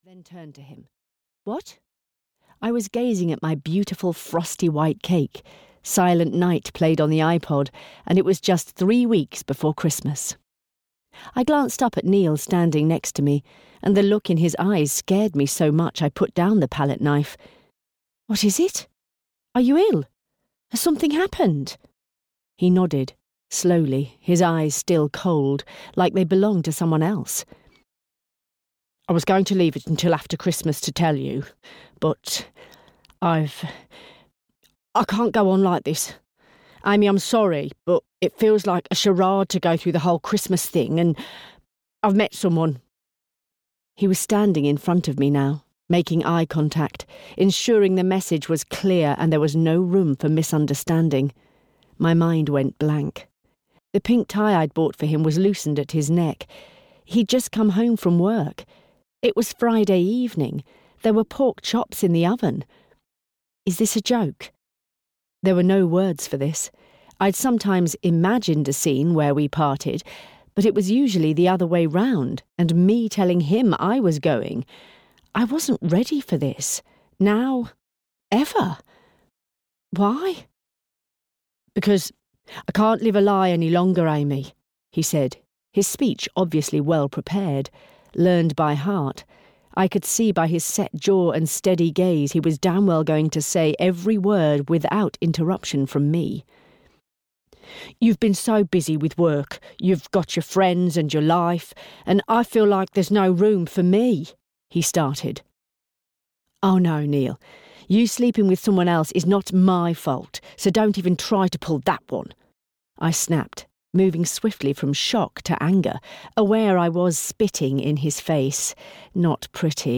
Bella's Christmas Bake Off (EN) audiokniha
Ukázka z knihy